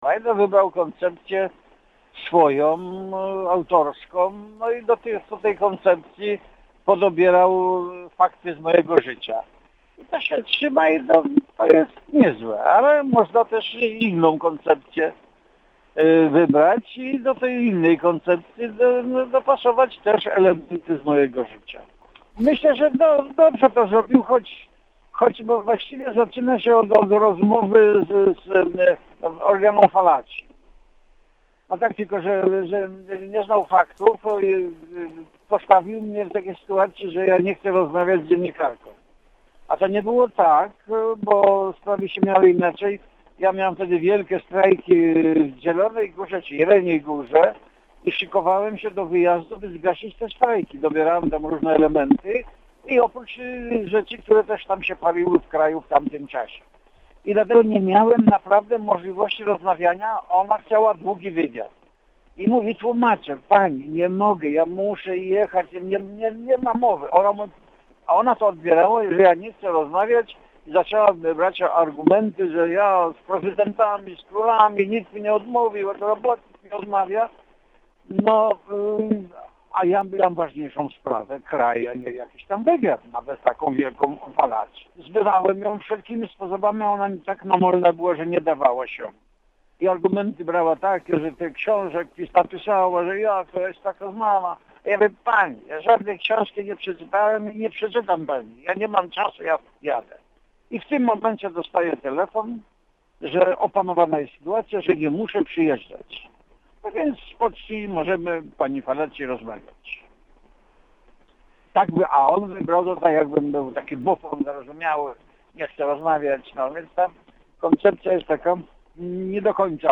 Cała rozmowa z Lechem Wałęsą: /audio/dok1/walesafilm.mp3 Tagi: Gdańsk kultura